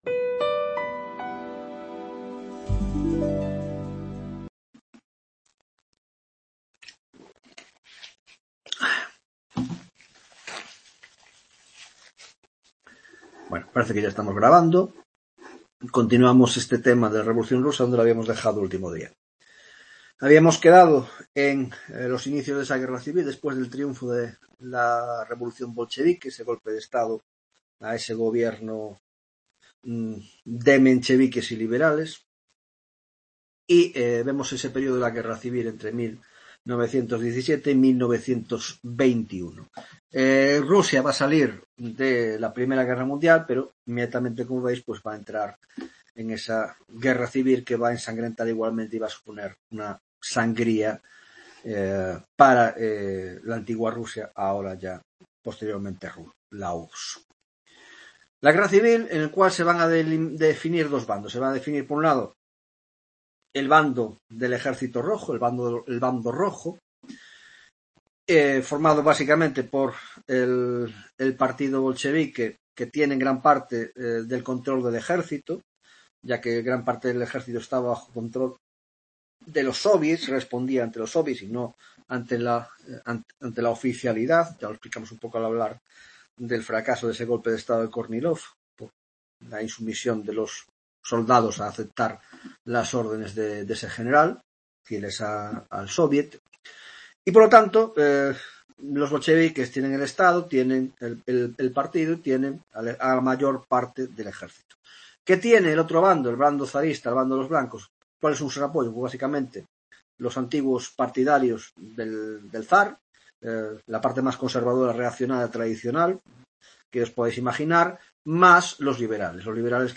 9ª tutoria de Historia Contemporánea